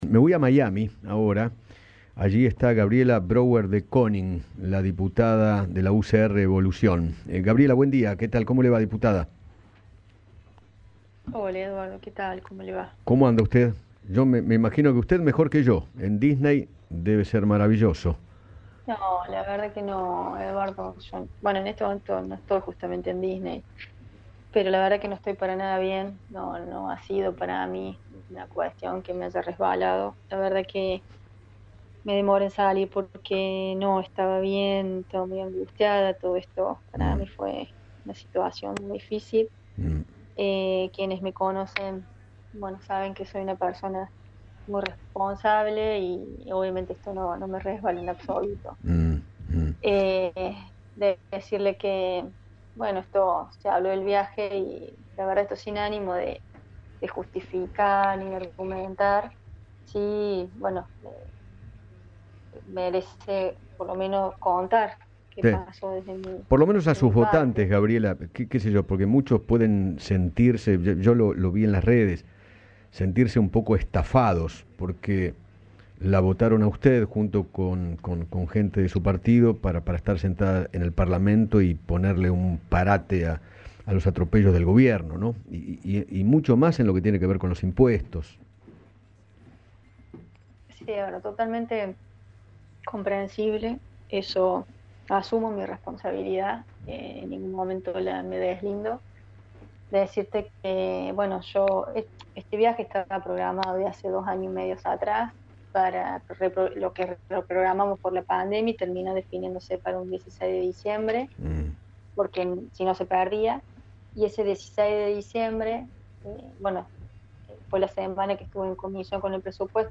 Gabriela Brouwer de Koning, diputada nacional, habló con Eduardo Feinmann sobre su ausencia a la votación de Bienes Personales porque estaba en Disney.